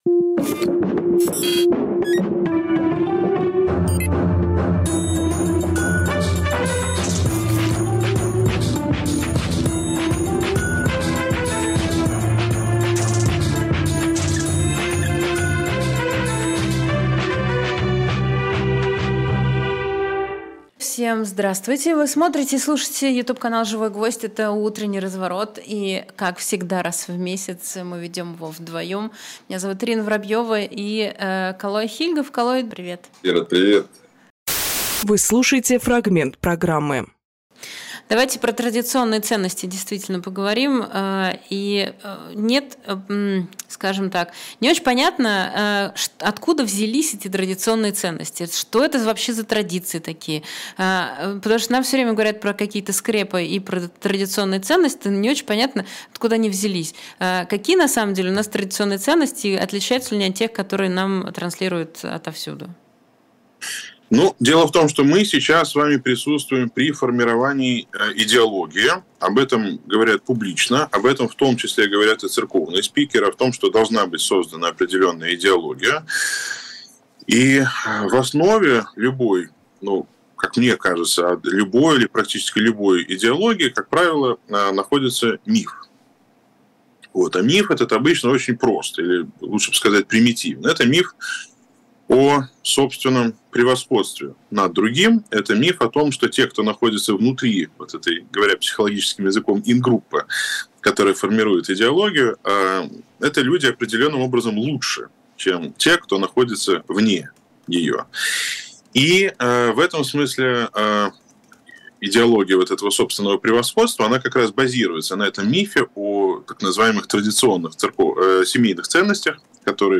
Фрагмент эфира от 06.11.23